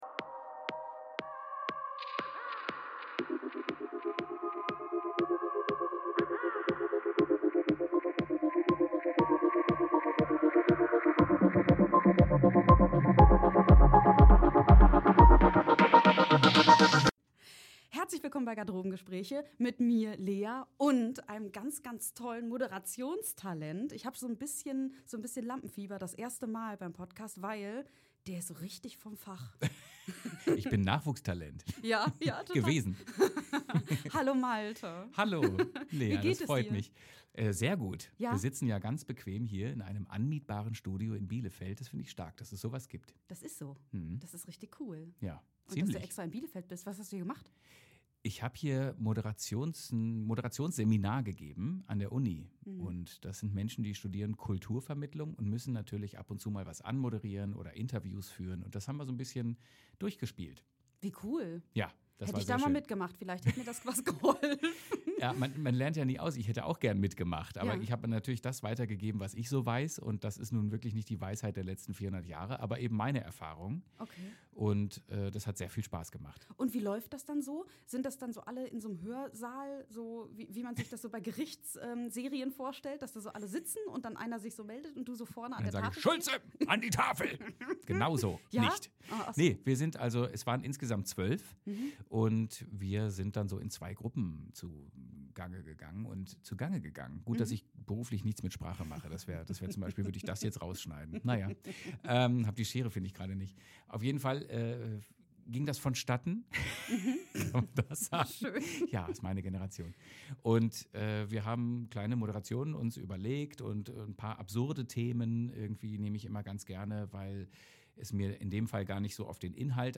In dieser Folge von Garderobengespräche ist Moderator Malte Arkona zu Gast. Gemeinsam sprechen wir über seine Zeit im Tigerenten Club, über die Liebe zur Bühne, über Lampenfieber und Leichtigkeit. Ein Gespräch voller Energie, Humor und Herz.